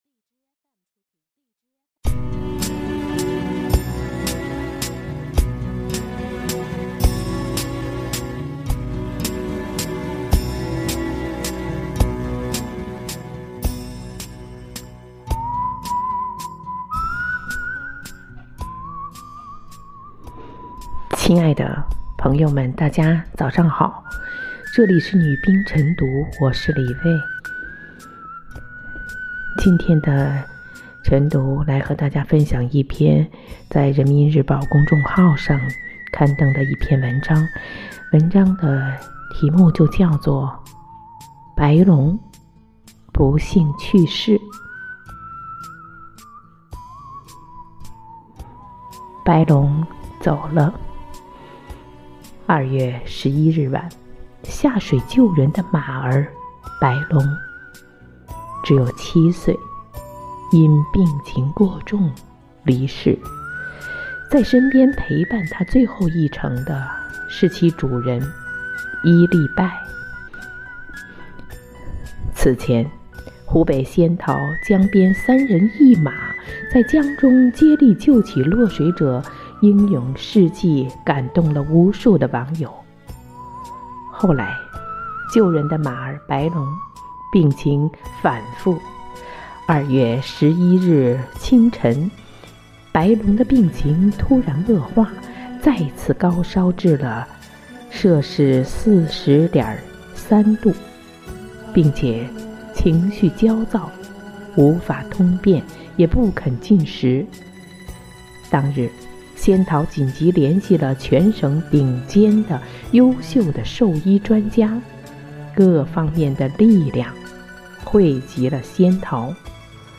每日《女兵诵读》“白龙”走了